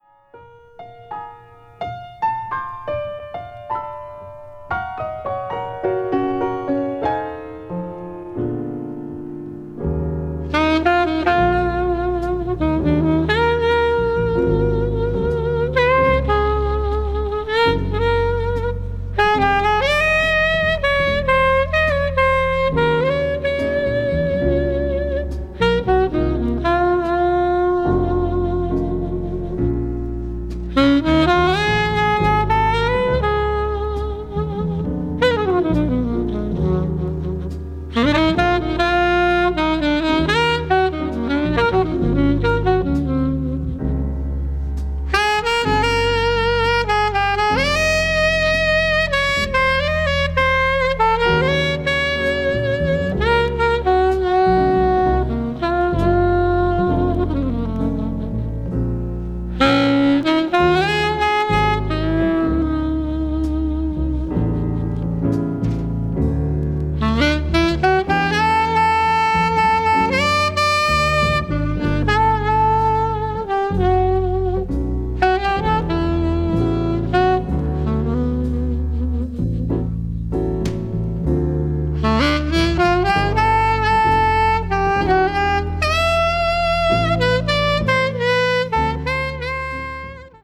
media : EX+/EX-(わずかにチリノイズが入る箇所あり,B:再生音に影響ない薄いスリキズ1本あり)
bebop   jazz standard   modern jazz   mood jazz